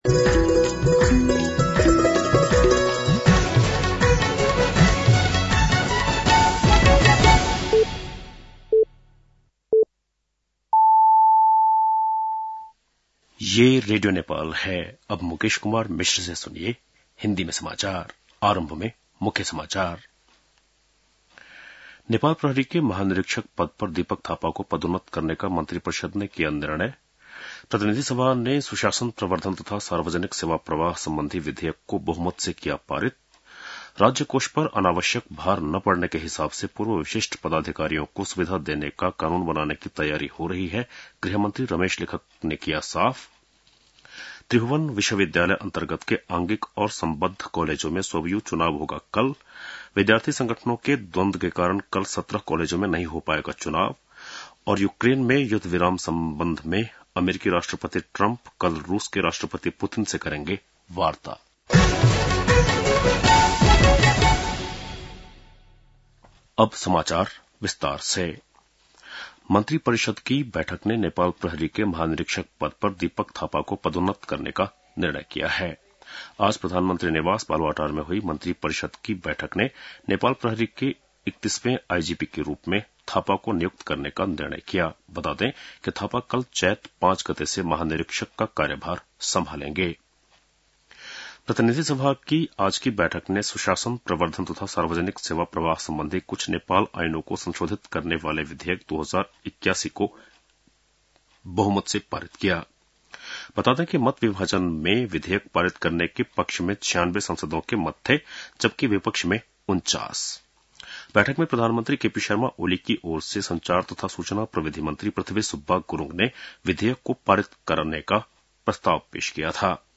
बेलुकी १० बजेको हिन्दी समाचार : ४ चैत , २०८१